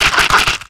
Cri d'Écrapince dans Pokémon X et Y.